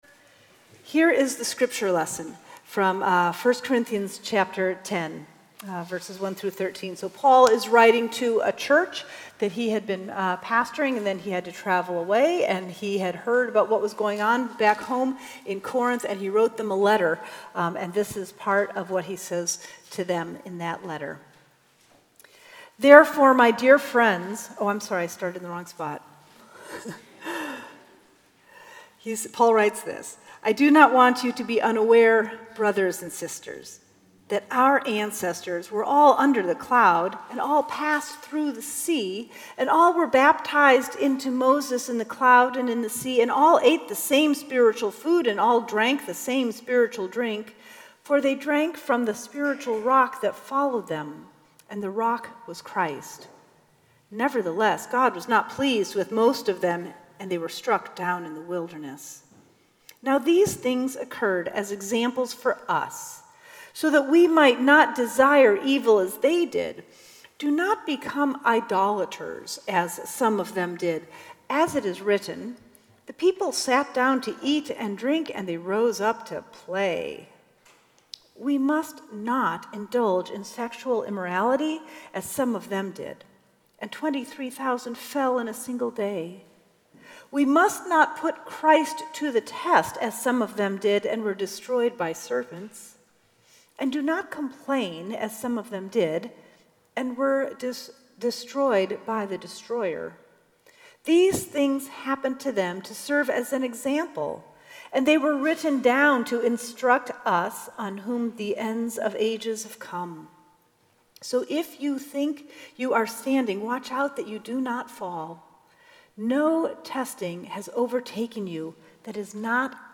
Sermons
October 1, 2017 Seventeenth Sunday after Pentecost